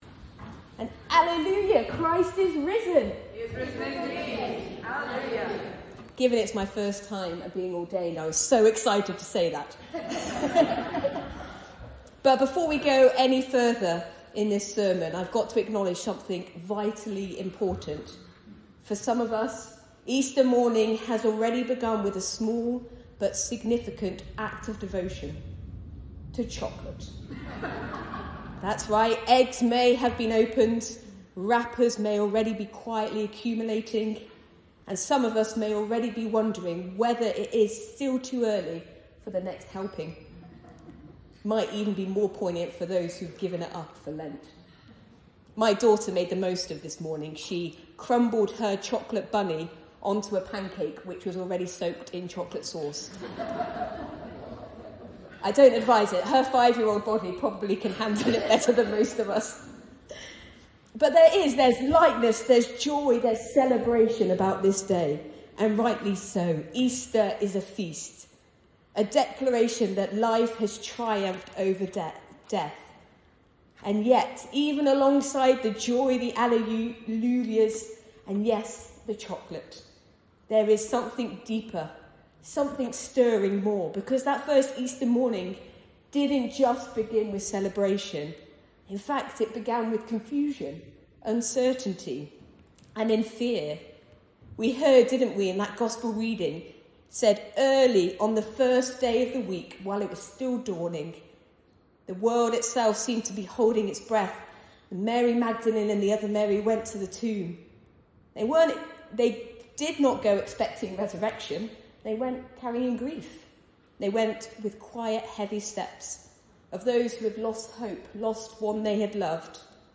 Sernon and Readings for Easter Sunday 5th April 2026